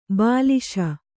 ♪ bāliśa